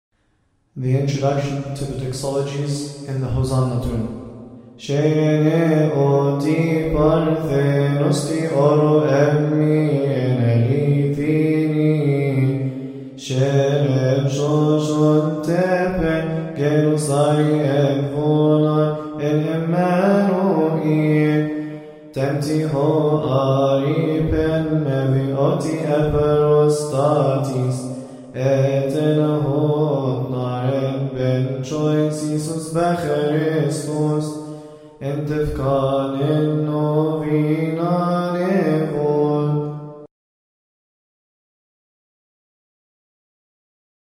All hymns must be chanted according to the Higher Institute of Coptic Studies.
Shaaniny,